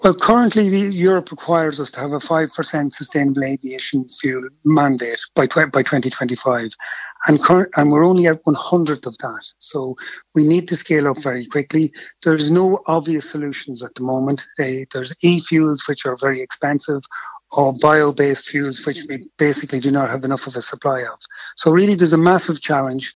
MEP Markey says there’s a long way to go for Ireland to meet its sustainable aviation fuel targets: